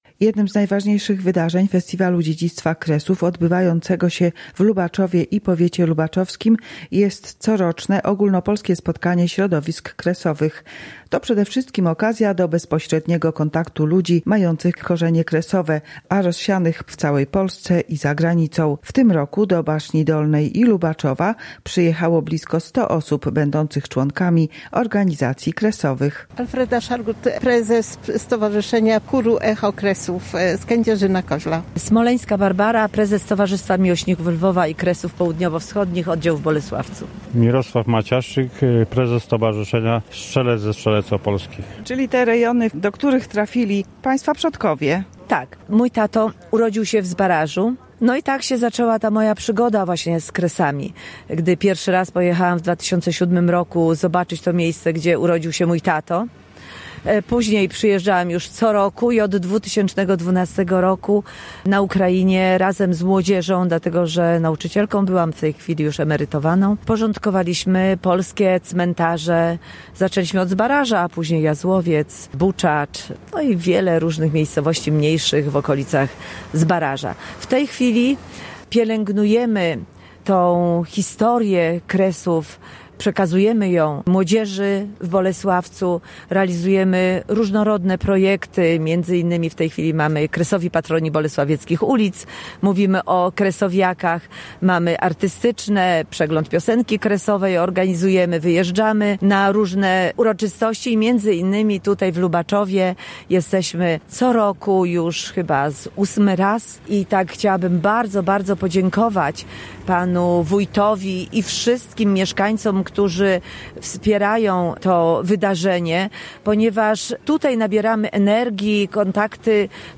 Jak podkreślali w rozmowie z nami przedstawiciele organizacji kresowych, trzeba dbać nie tylko o zachowanie tradycji, ale też propagować wiedzę o Kresach wśród kolejnych pokoleń.